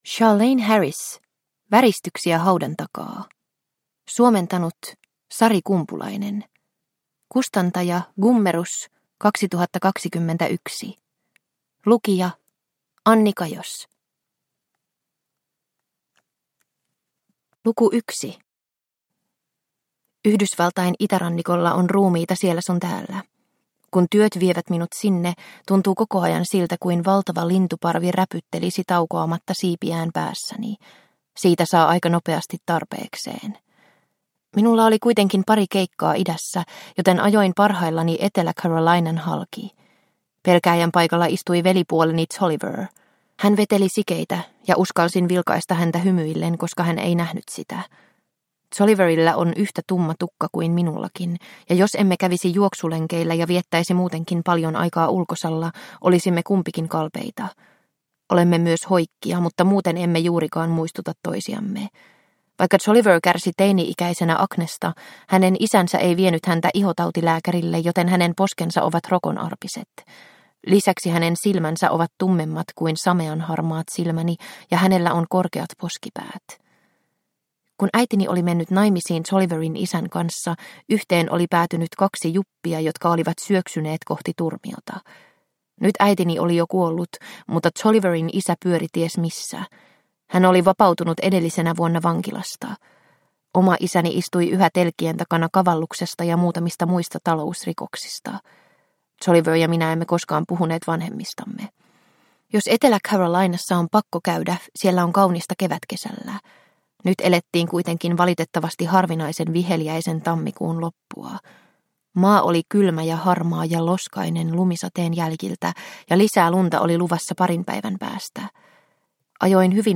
Väristyksiä haudan takaa – Ljudbok – Laddas ner